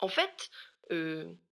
VO_ALL_Interjection_11.ogg